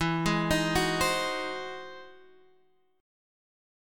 E Augmented Major 9th